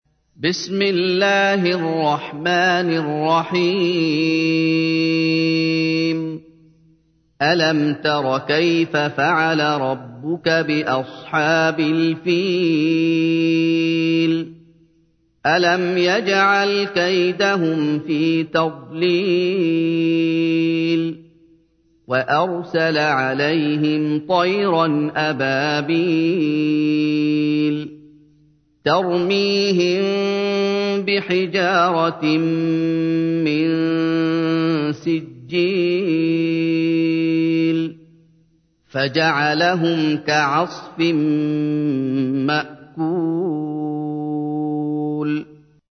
تحميل : 105. سورة الفيل / القارئ محمد أيوب / القرآن الكريم / موقع يا حسين